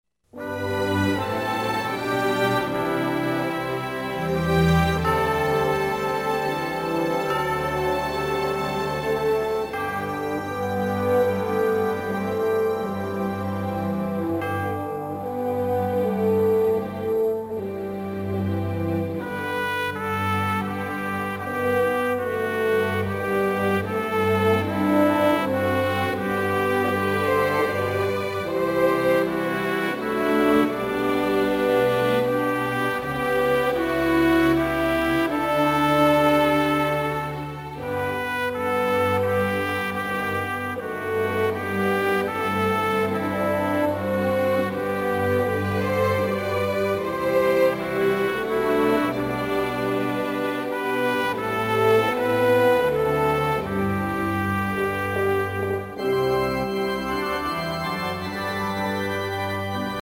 Performers: SMS Orchestra
Project: Instrumental Praise